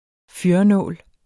Udtale [ ˈfyɐ̯ʌ- ]